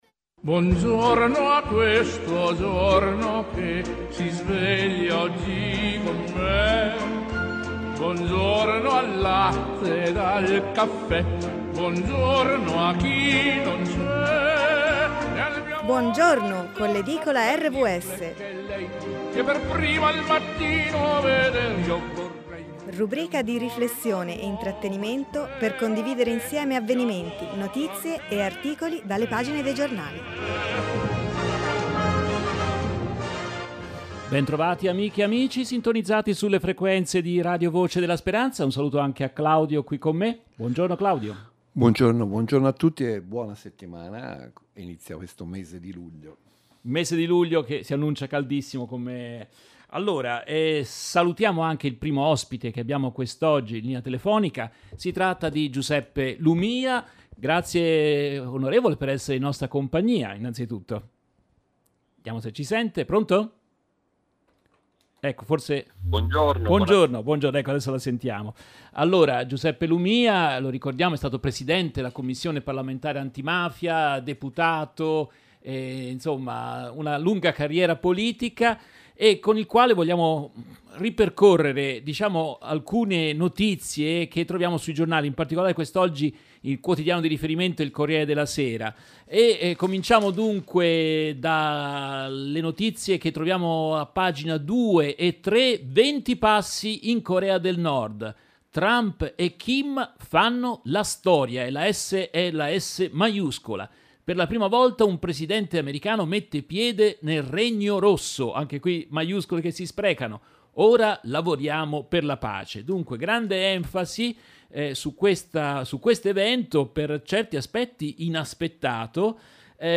commentano con i loro ospiti alcuni articoli presenti sui giornali di oggi